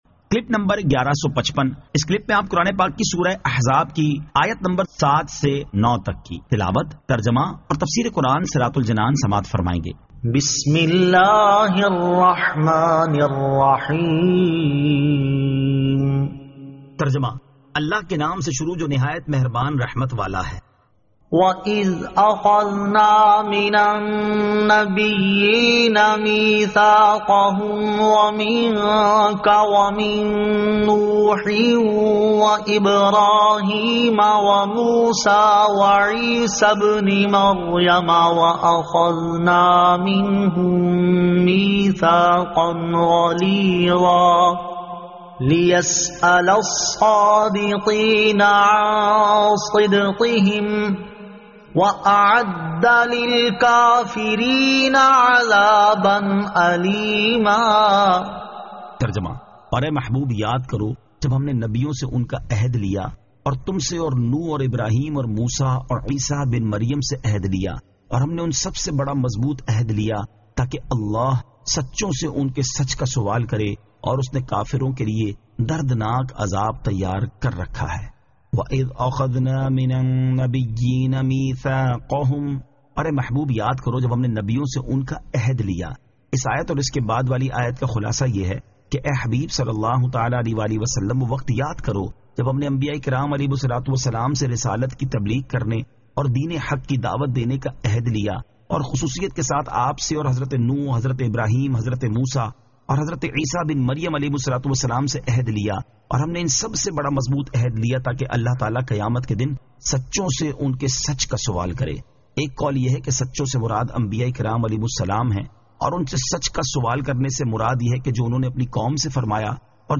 Surah Al-Ahzab 07 To 09 Tilawat , Tarjama , Tafseer